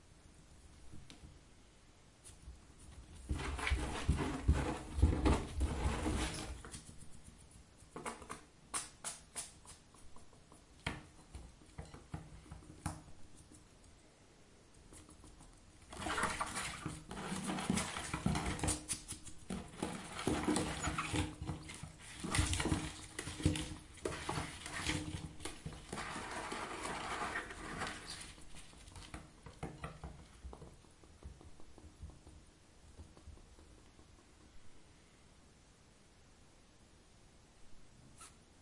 На этой странице собраны натуральные звуки ежей: фырканье, шуршание листьев и другие характерные шумы.
Звук ёжика скребущего лапами по стенкам коробки